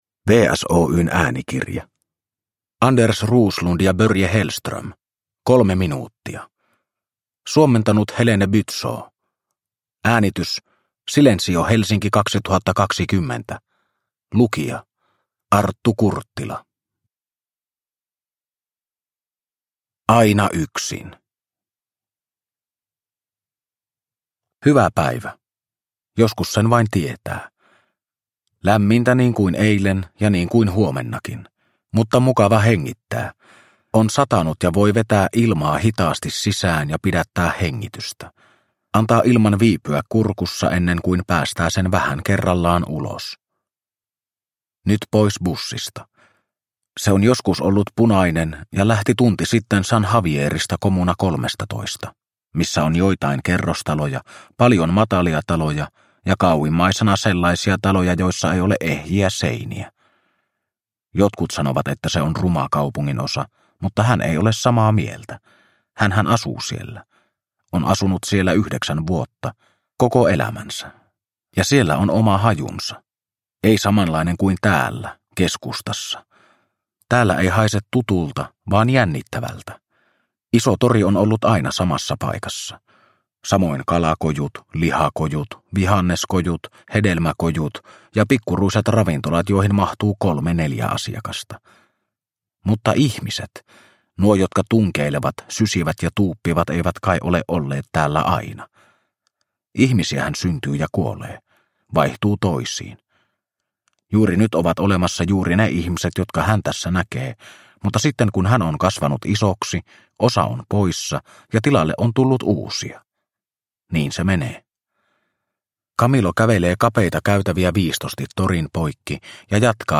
Kolme minuuttia – Ljudbok – Laddas ner